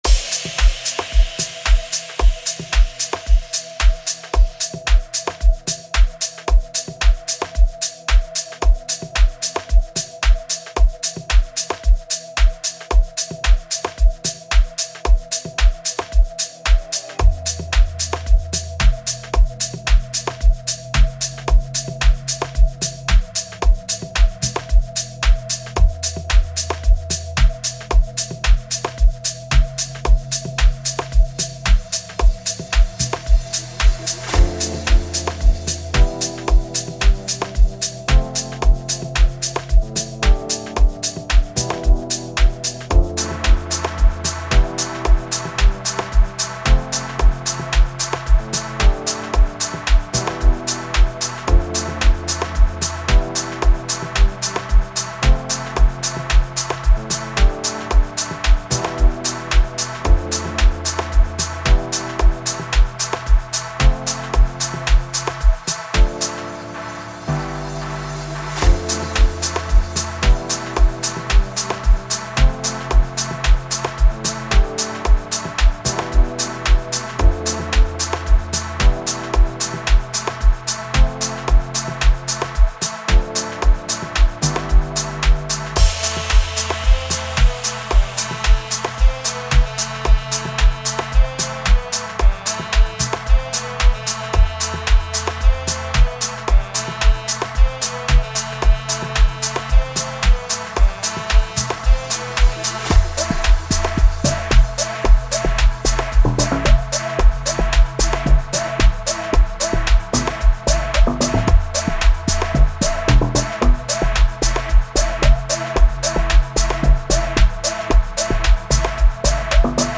Home » Amapiano
vibrant new song